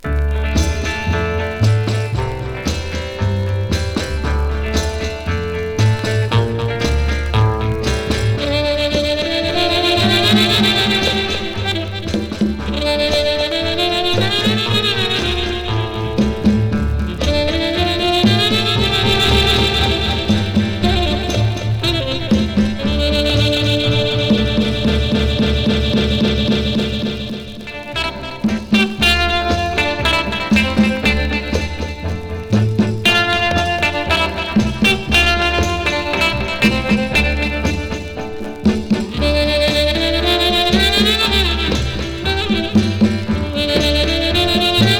Rock & Roll, Surf　USA　12inchレコード　33rpm　Mono